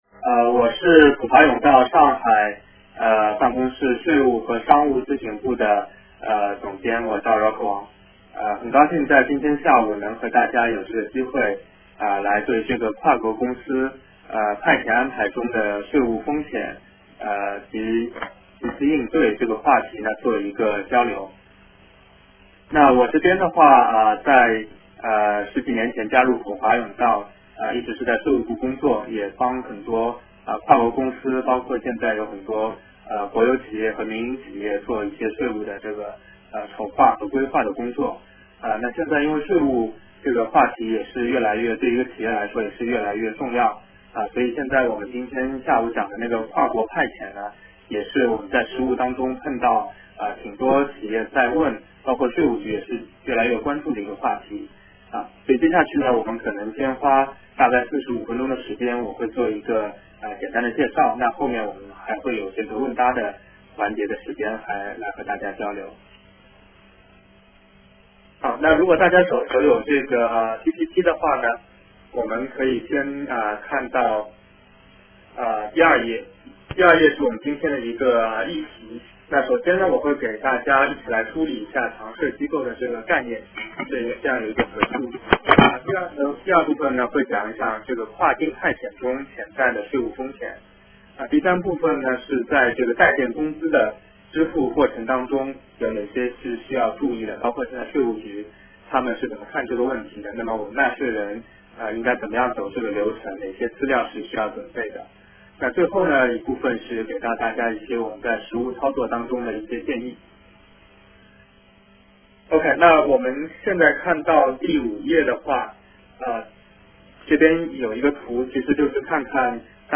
电话会议
Webinar